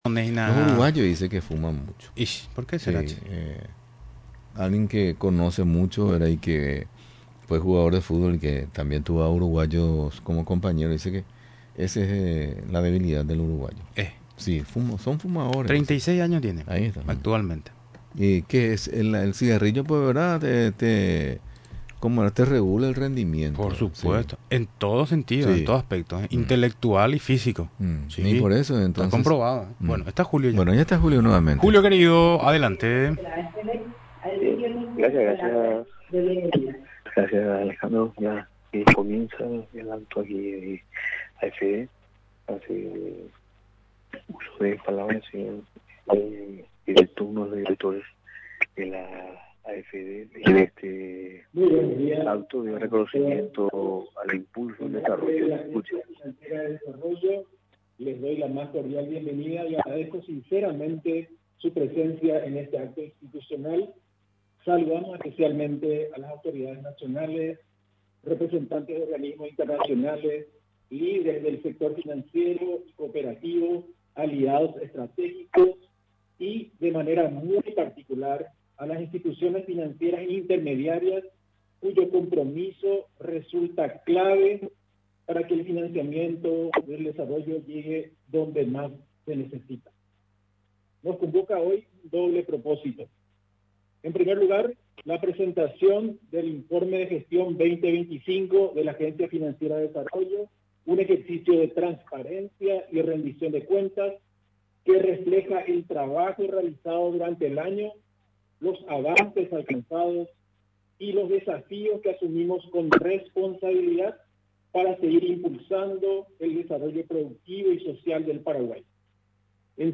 El acto de informe de gestión de la AFD se realizó en el Salón Bicentenario del Paseo La Galería.